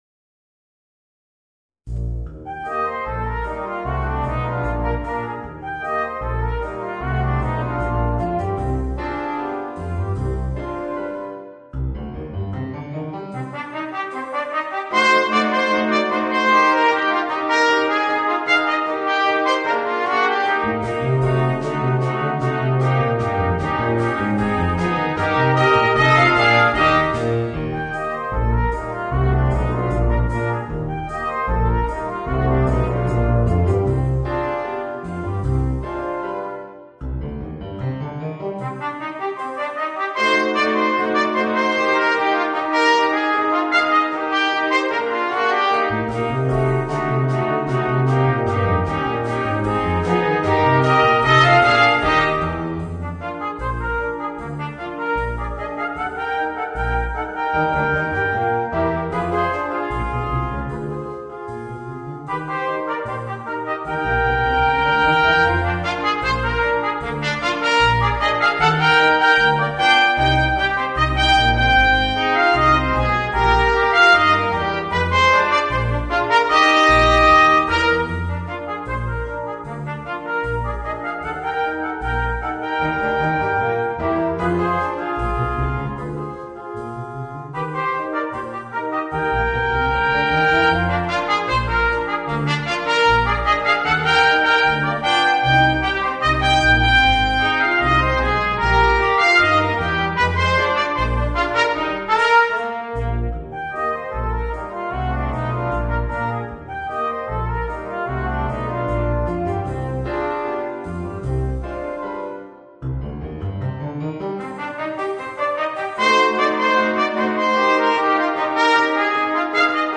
Voicing: 2 Trumpets, Horn, Trombone and Drums